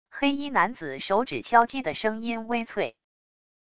黑衣男子手指敲击的声音微脆wav音频